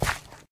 sounds / step / gravel2.ogg
gravel2.ogg